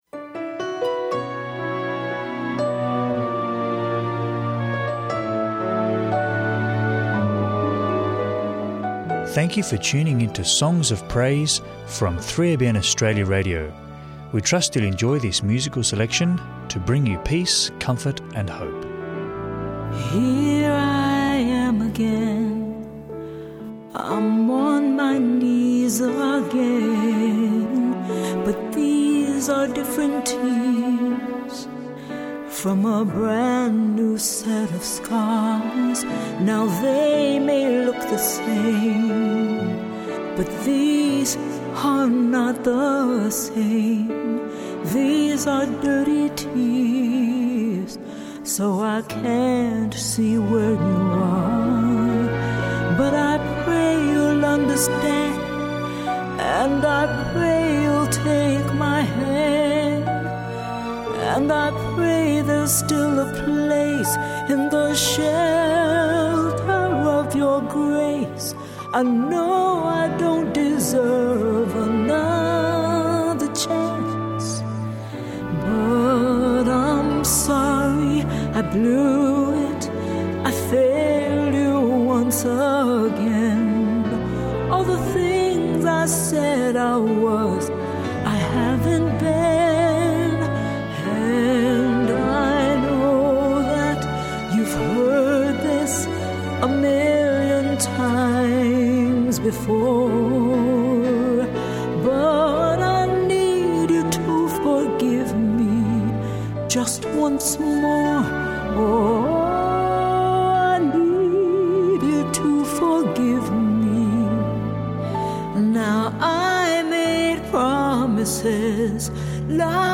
Enjoy musical reflection to encourage, uplift and draw you into a closer relationship with our loving Saviour, Jesus Christ.